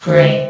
S.P.L.U.R.T-Station-13 / sound / vox_fem / gray.ogg
CitadelStationBot df15bbe0f0 [MIRROR] New & Fixed AI VOX Sound Files ( #6003 ) ...